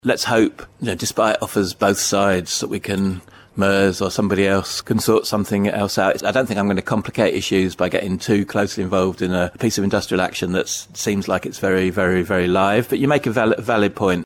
Speaking on Manx Radio's Budget special , Chris Thomas MHK was asked whether any of the money being used from government's reserves could be spent on meeting the demands of the bus drivers: Listen to this audio